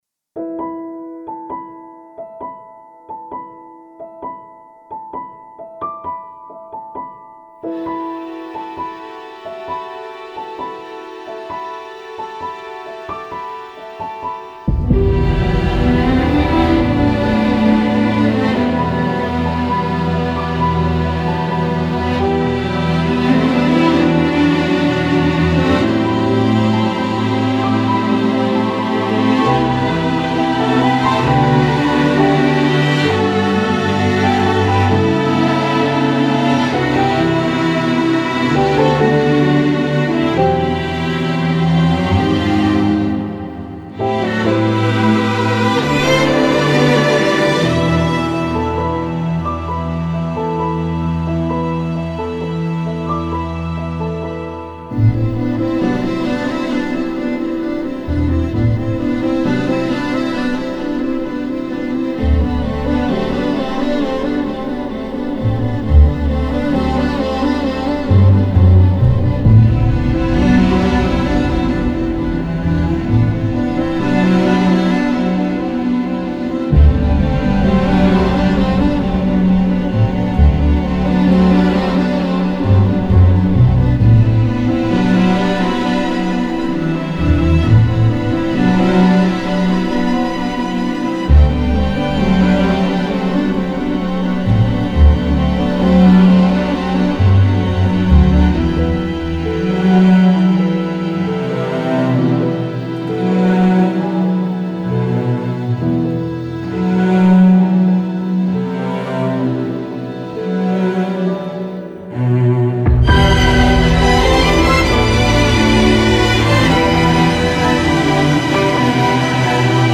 duygusal hüzünlü üzgün fon müziği.